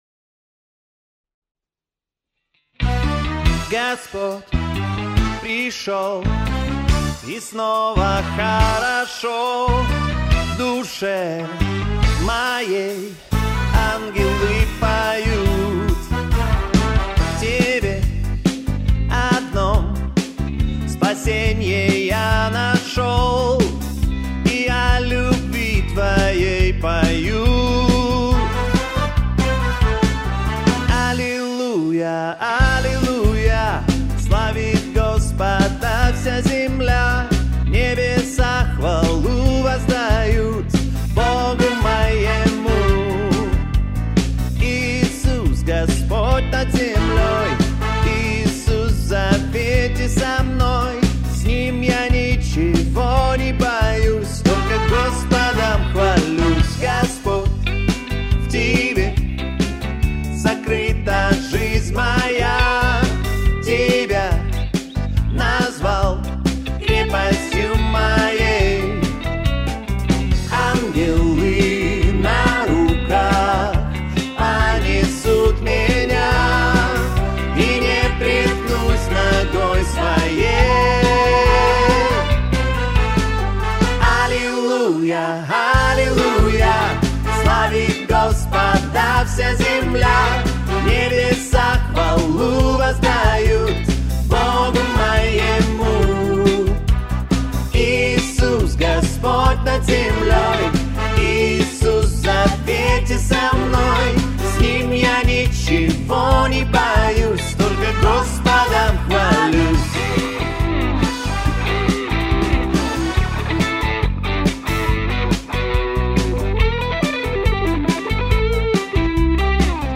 песня
437 просмотров 361 прослушиваний 100 скачиваний BPM: 140